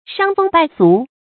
注音：ㄕㄤ ㄈㄥ ㄅㄞˋ ㄙㄨˊ
傷風敗俗的讀法